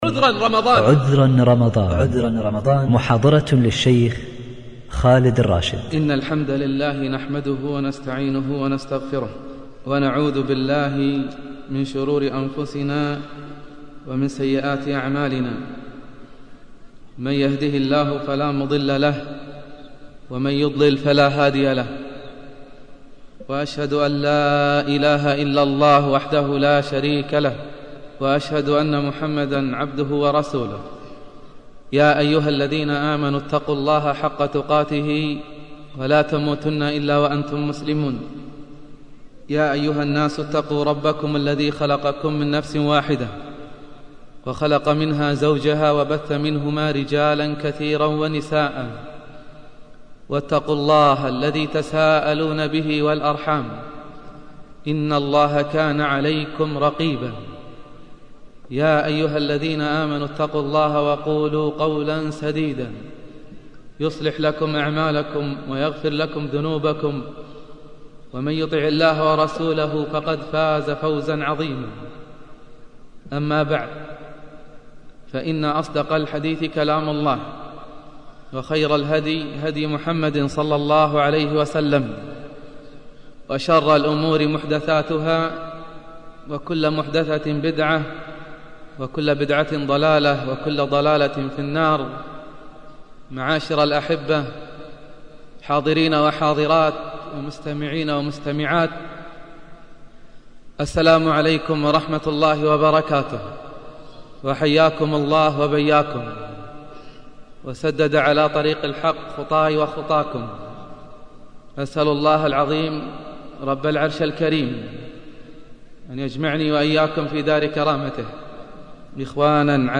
ختم بدعاء جامع أن يرزقنا الله صيامًا وقيامًا مقبولًا. المحاضرات الصوتية